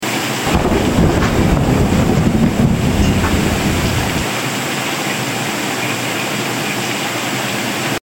Relaxing Sounds  Close your eyes and relax to the natural sound of rain on the hill 🌿. Every drop brings peace and warmth to your heart 😴.